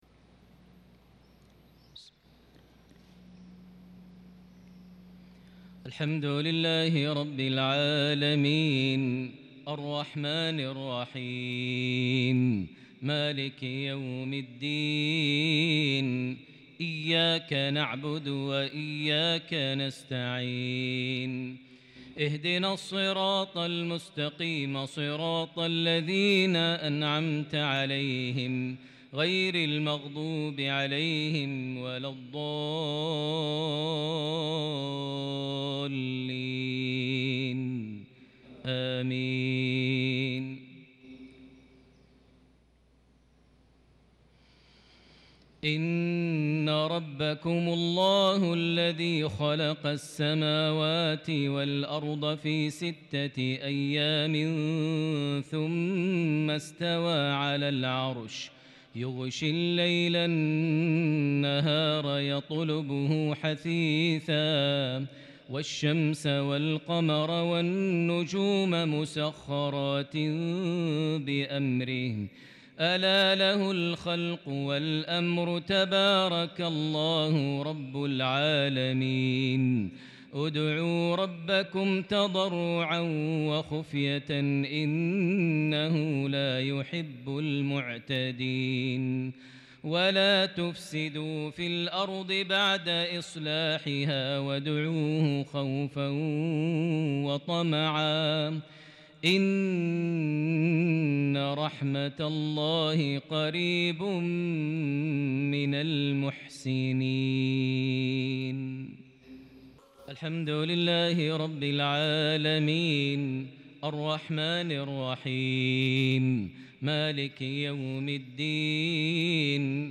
صلاة المغرب من سورة الأعراف | 30 محرم 1443هـ | mghrip 7-9- 2021 prayer from Surah Al-Araf 54- 58 > 1443 🕋 > الفروض - تلاوات الحرمين